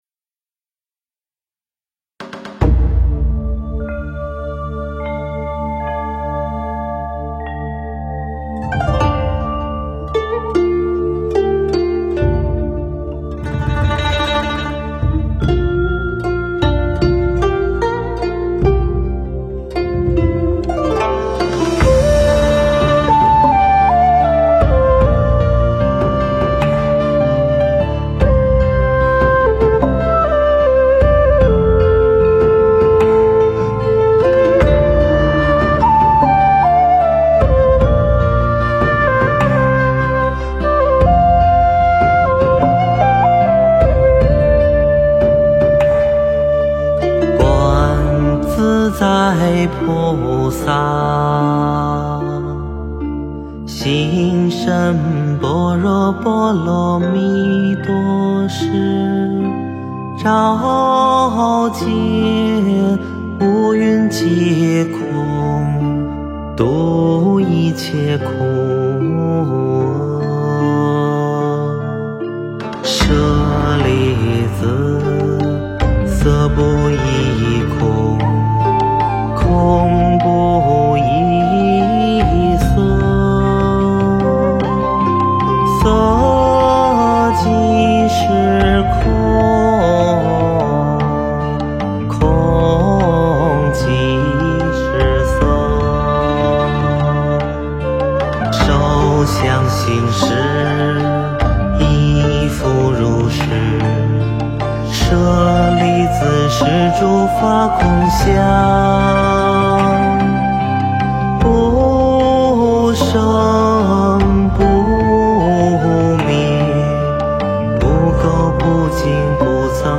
诵经
佛音 诵经 佛教音乐 返回列表 上一篇： 安逸忏心 下一篇： 观音灵感真言(梦授咒